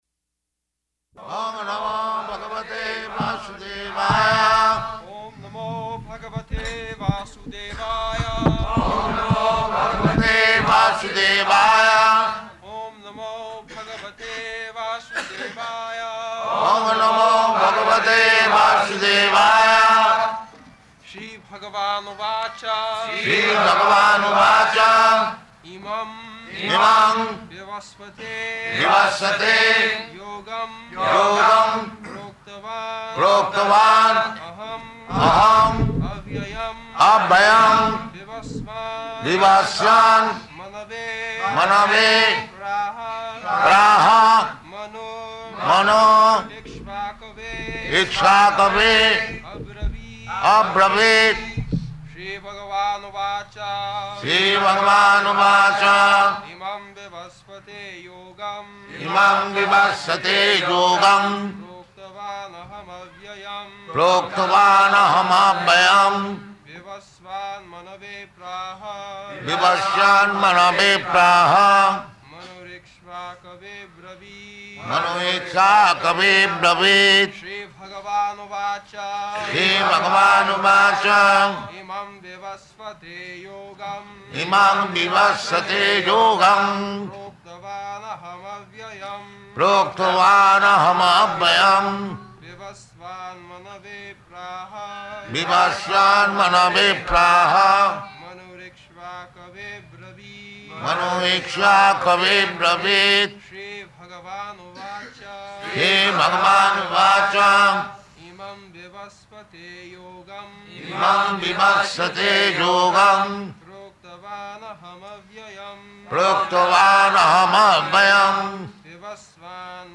March 21st 1974 Location: Bombay Audio file
[Prabhupāda and devotees repeat] [leads chanting of verse]